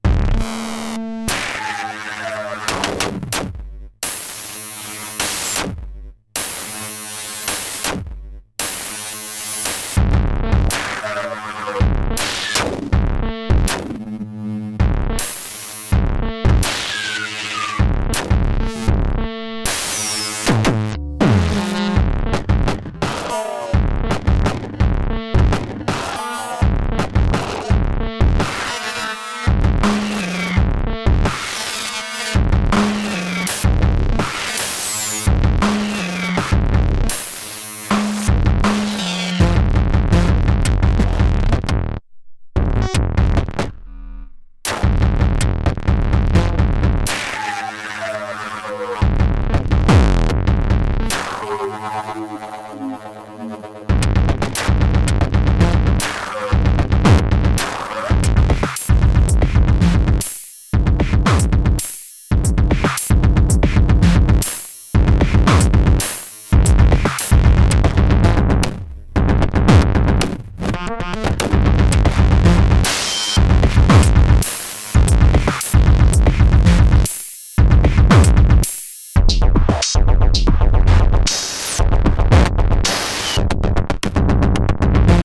Crap sounds ahead.